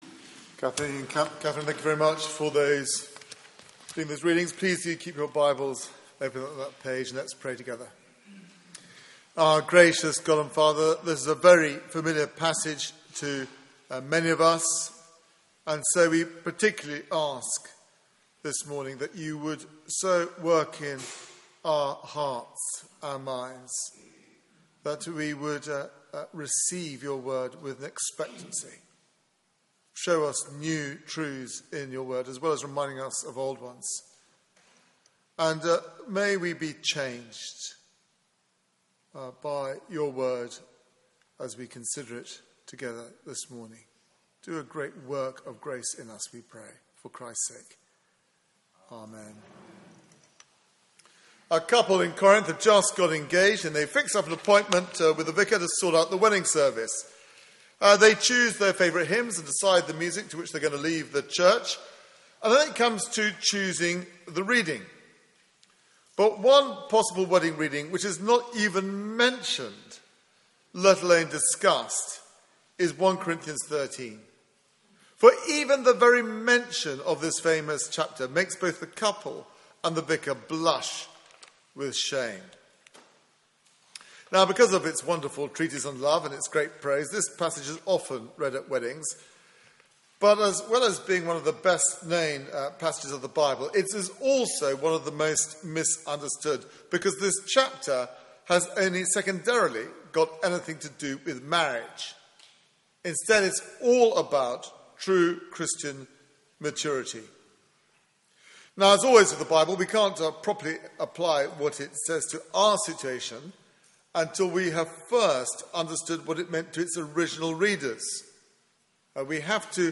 Media for 9:15am Service on Sun 01st Nov 2015 09:15
Theme: Love supreme Sermon